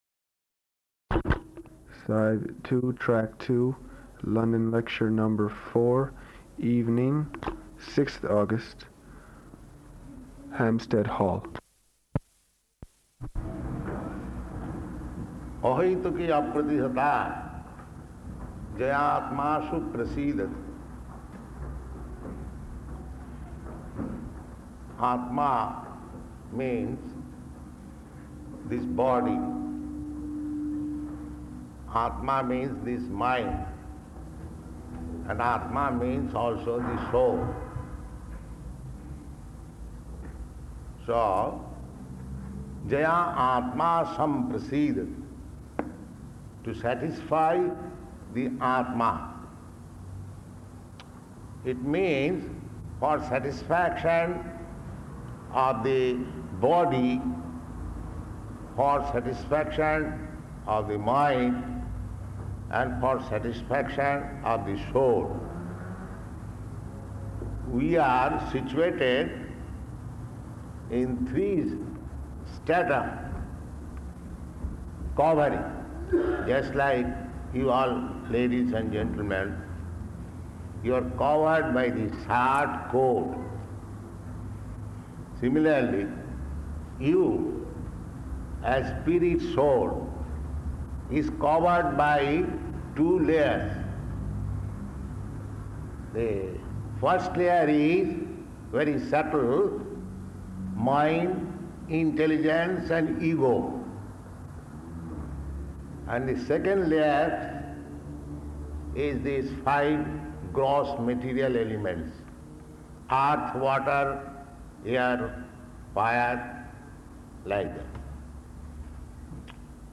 Śrīmad-Bhāgavatam 1.2.6 Hampstead Hall
Type: Srimad-Bhagavatam
Location: London